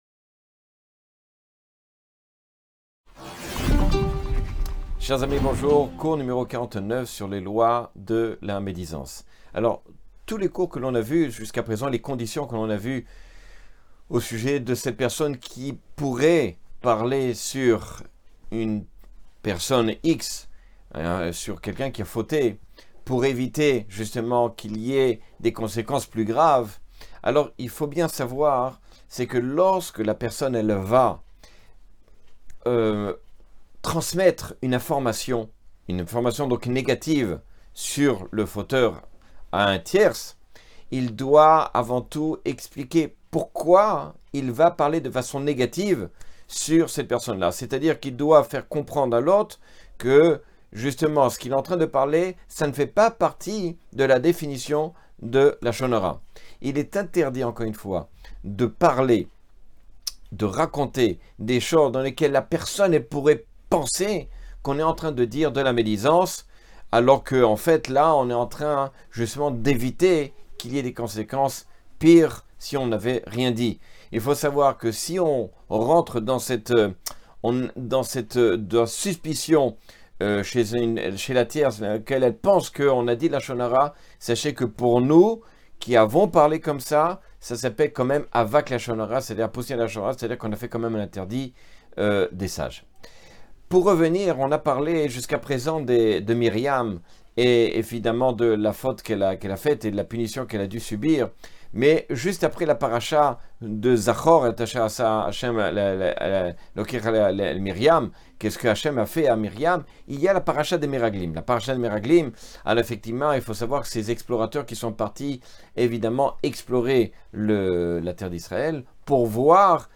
Cours 49 sur les lois du lashon hara.